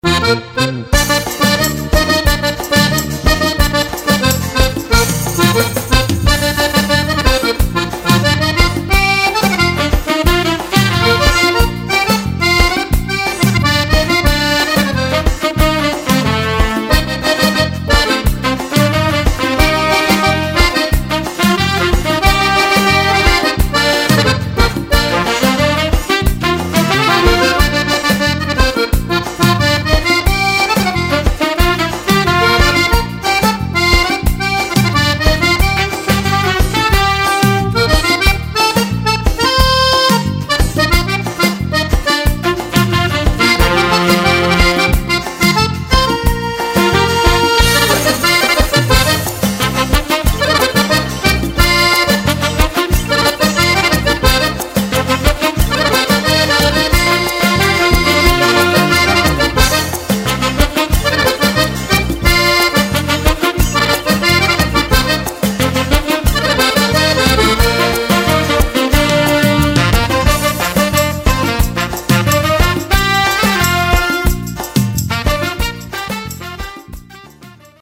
Bajon
Fisarmonica